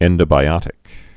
(ĕndə-bī-ŏtĭk)